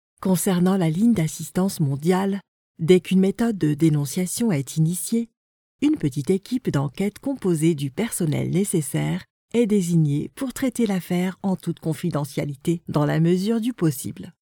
Female
Most importantly, I handle all the technical mastering work (-23dB to -18dB| kbps) and formatting in my home studio.
Explainer Videos
Natural Speak
Whiteboard Video
0723demo_explainer_whteboard_video.mp3